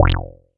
Wet_Bass_F2.wav